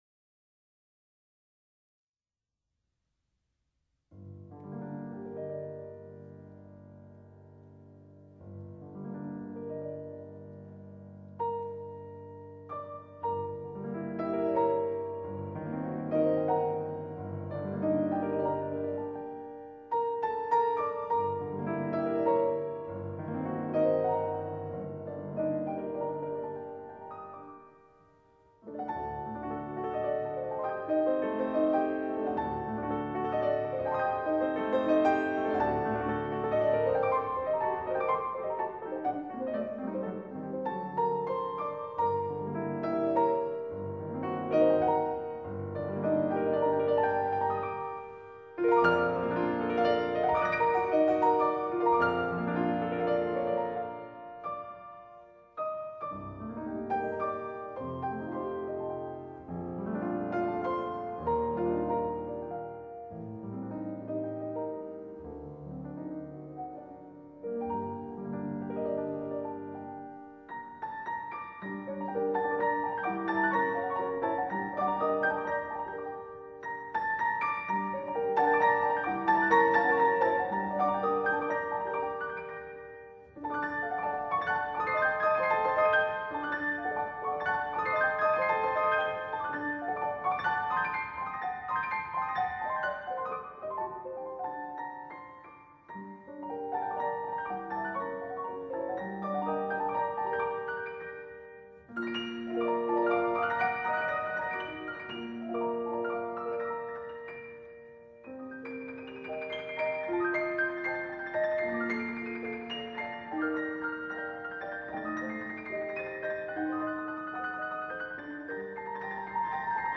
很难的双钢琴组曲。流动的旋律，抒情而充满幻想~~
钢琴